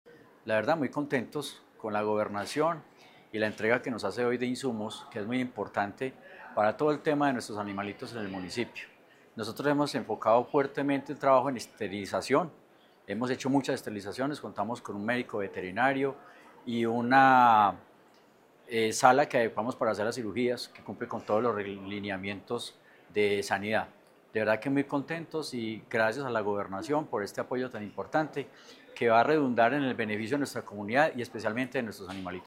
Óscar Eduardo Alzate Cano, alcalde de Filadelfia.